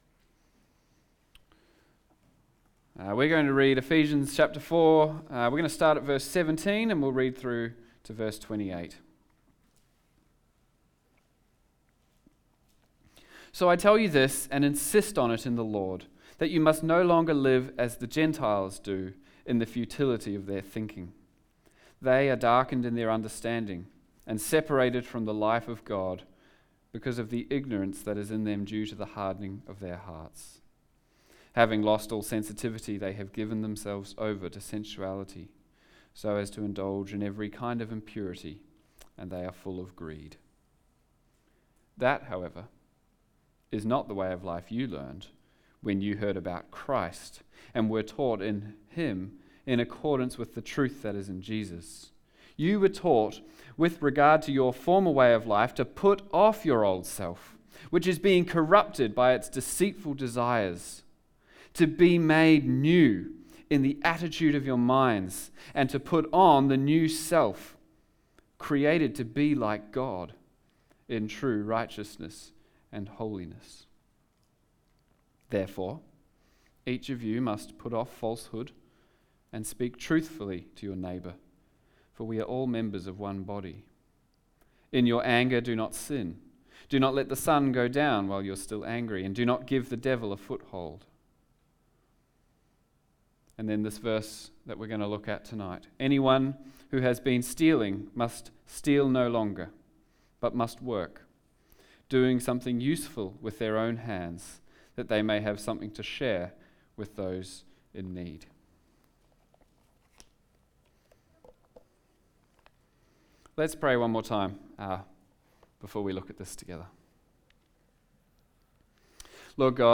Sermons | Riverbank Christian Church